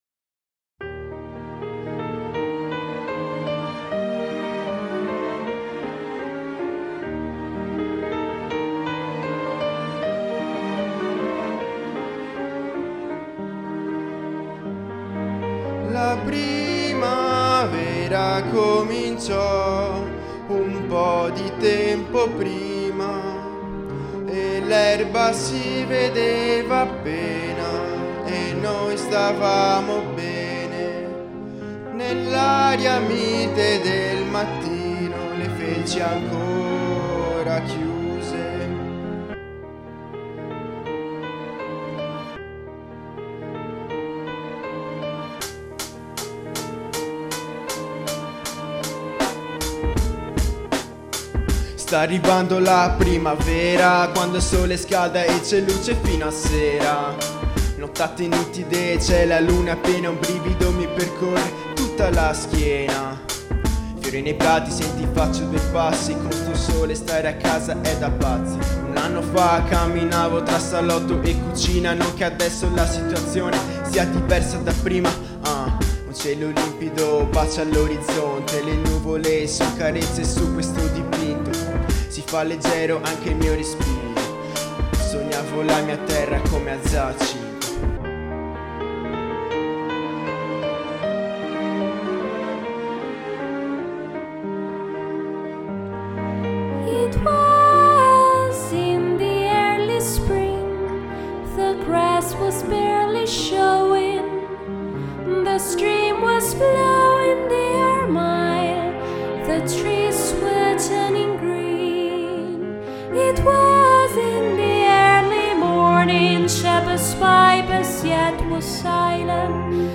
ecletticamente rivisitata ed interpretata